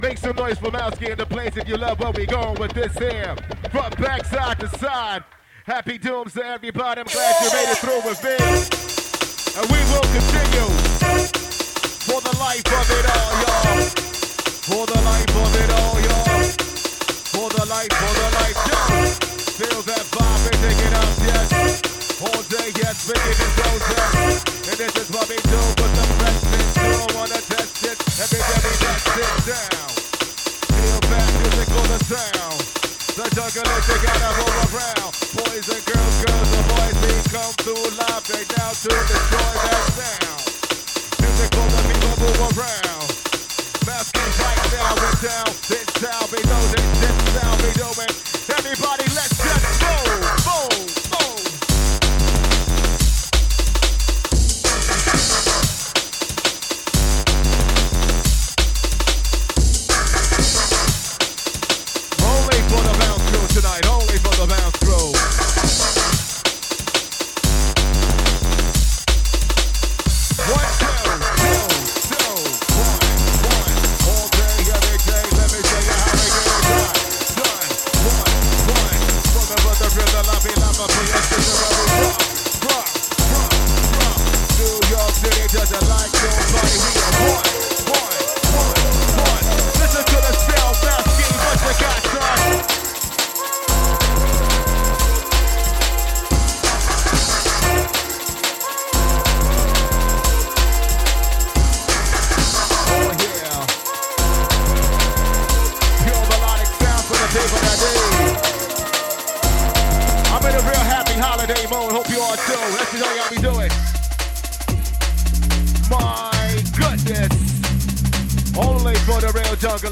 Live from Le Poisson Rouge – NYC
drum n’ bass